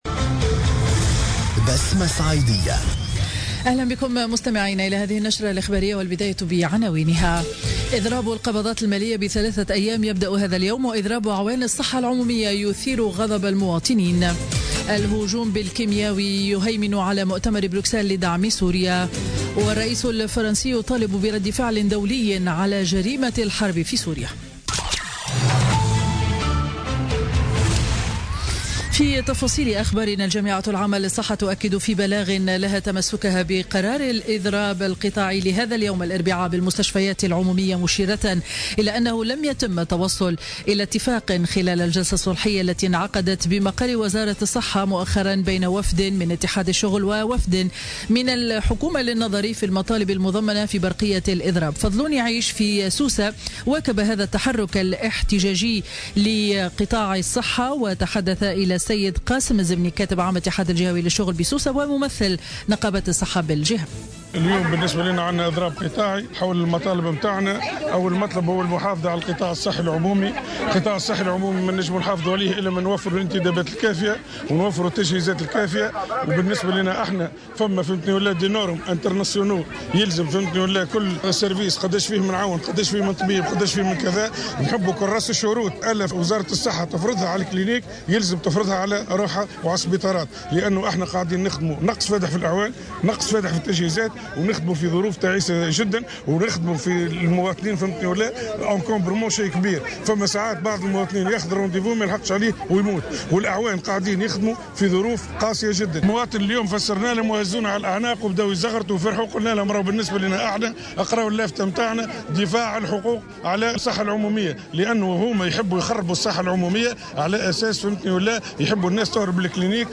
نشرة أخبار منتصف النهار ليوم الاربعاء 5 أفريل 2017